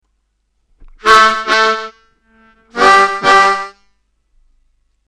Funkin’ it up on the blues harmonica